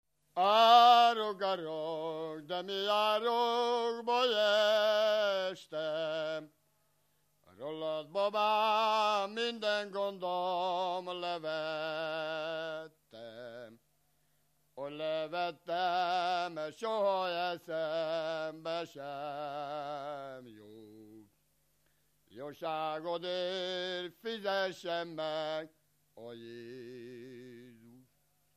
Erdély - Alsó-Fehér vm. - Magyarózd
ének
Stílus: 6. Duda-kanász mulattató stílus
Kadencia: 4 (1) VII 1